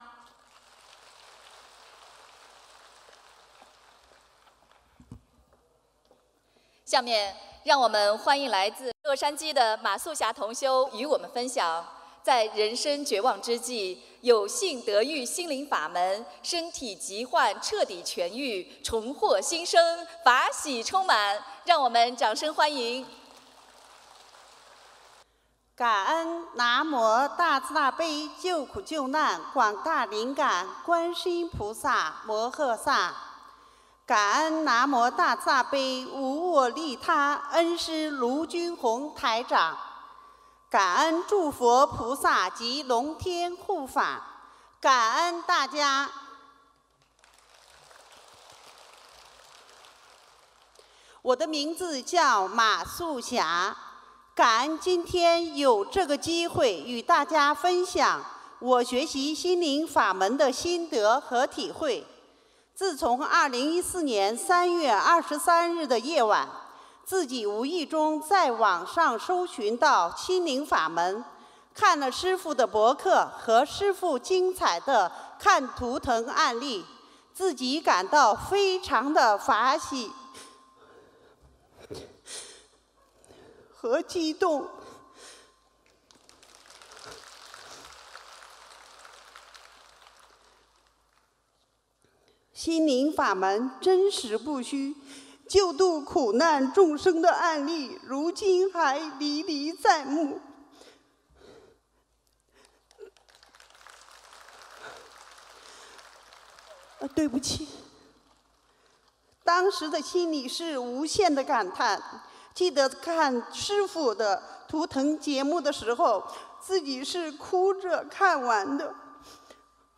音频：学佛后身体病痛彻底痊愈--2016年9月11日 美國洛杉矶·同修分享